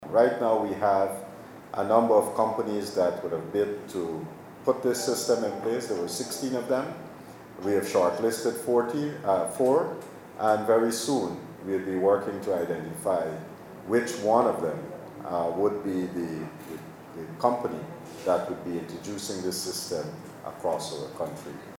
This update was provided during the commissioning of a digital X-Ray machine at Mahaicony.